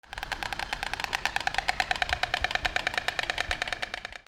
/ D｜動物 / D-05 ｜鳥
サギのような鳥 くちばしを鳴らす
MKH416